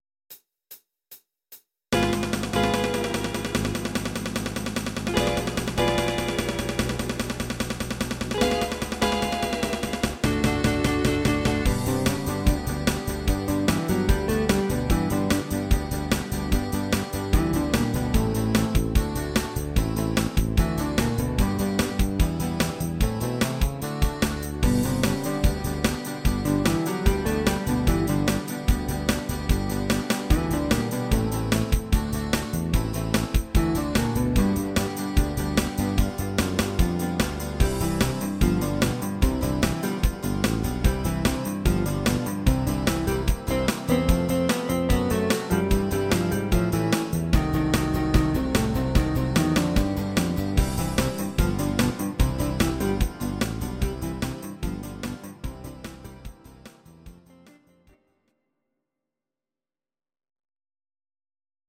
instr. guitar